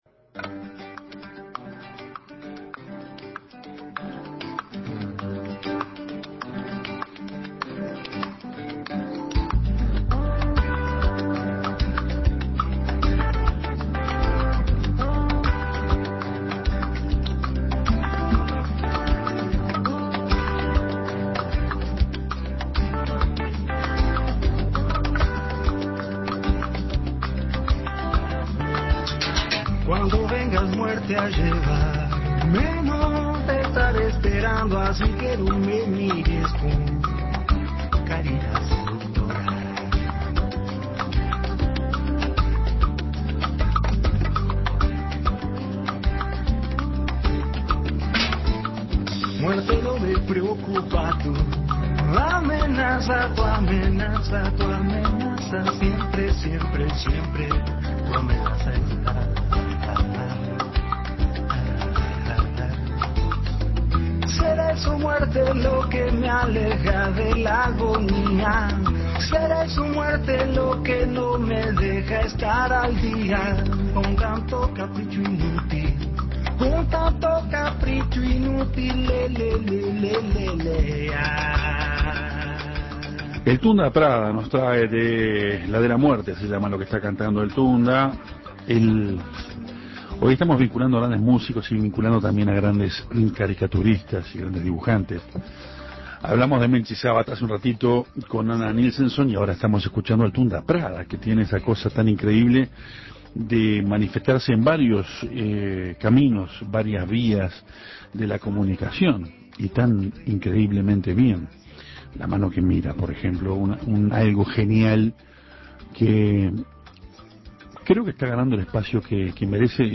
La división turismo de la Intendencia de Montevideo apuesta a esta nueva actividad cultural que consiste en visitas guiadas al cementerio Central. Sobre esto conversamos con Fernando González, director de Turismo de la comuna capitalina.